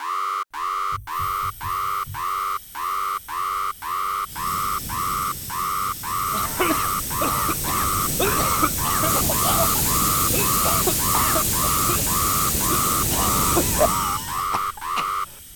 Adding server room sound effects and button to trigger them
Server alarm sequence sound.mp3